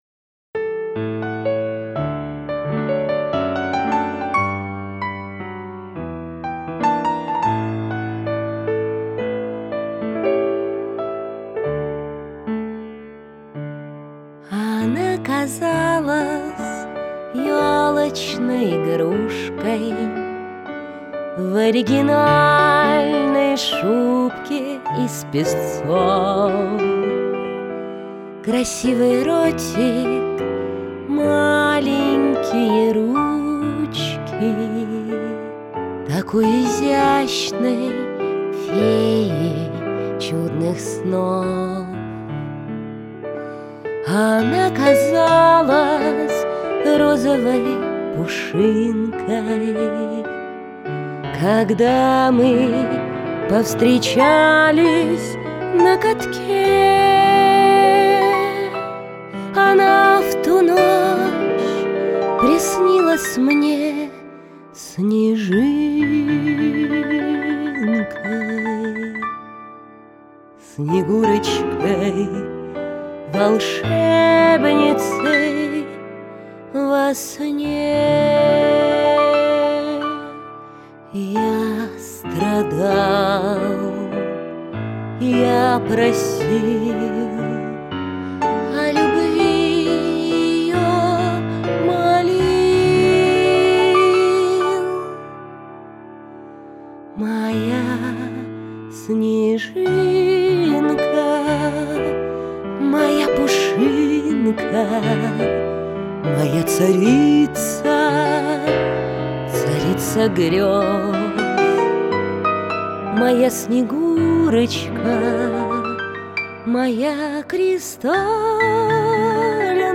полу-профессиональный женский вокал, очень чувственный и артистичный
Снежинка ( малоизвестный иронически-сентиментальный романс Воляртинского )